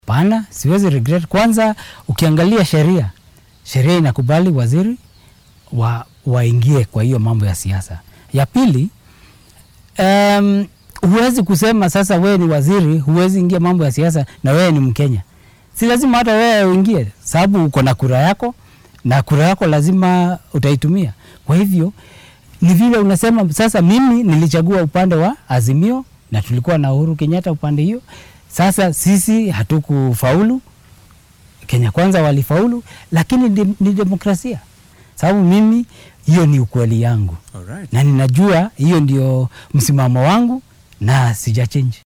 Wasiirkii hore ee wasaaradda warfaafinta , isgaarsiinta iyo tiknolojiyadda dalka , Joe Mucheru oo wareysi gaar ah siiyay telefishinka maxalliga ee Citizen ayaa sheegay inuunan marnaba ka qoomameynayn tallaabadii uu taageero siyaasadeed ugu muujiyay musharraxii madaxweyne ee isbeheysiga Azimio La Umoja-One Kenya Raila Odinga.